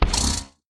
Sound / Minecraft / mob / horse / skeleton / hit3.ogg
hit3.ogg